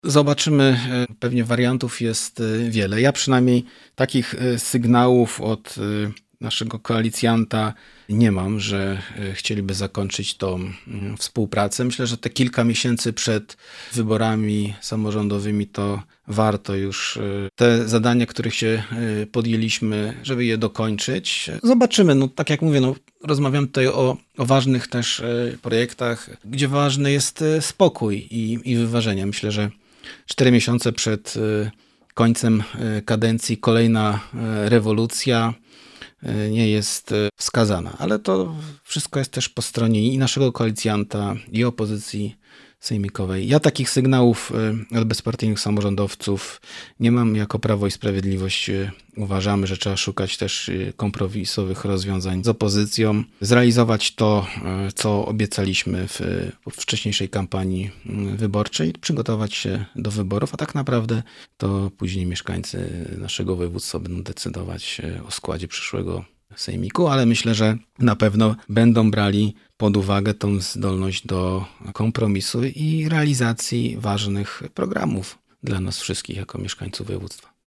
– Nie mam sygnałów od kolicjantów, by chcieliby zakończyć współpracę, mówi Marcin Krzyżanowski – wicemarszałek województwa dolnośląskiego.